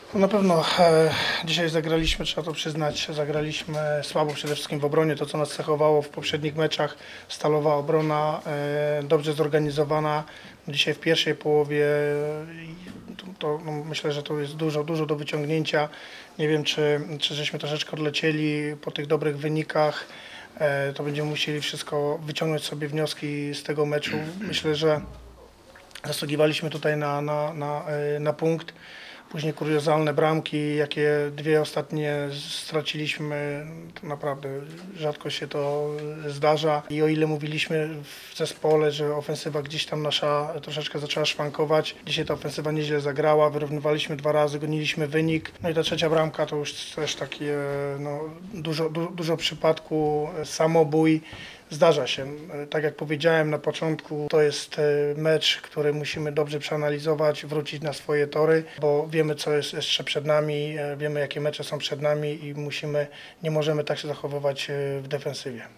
Na pomeczowej konferencji trener gości, Marek Saganowski, tłumaczył końcowy wynik błędami w obronie swoich podopiecznych:
27 IV 2022;; Suwałki – Stadion Miejski; eWinner 2 liga; Wigry – Motor Lublin 3:2; Marek Saganowski